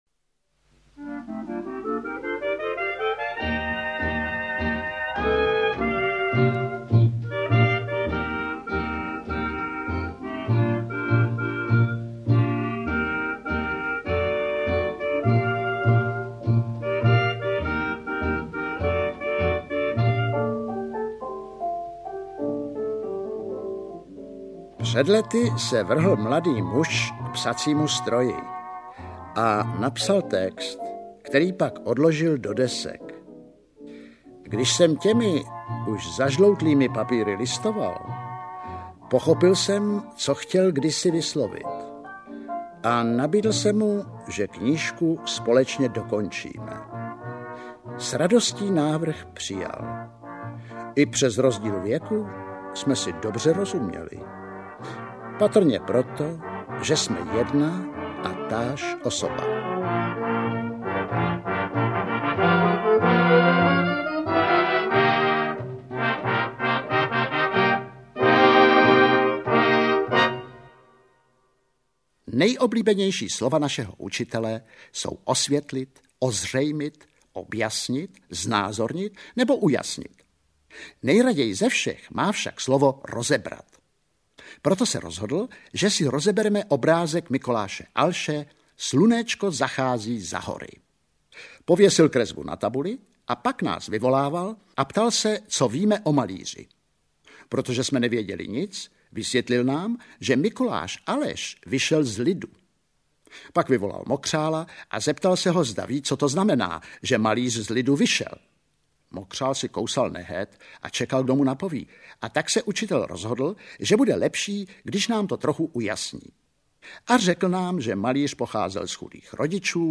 Interpret:  Ivan Kraus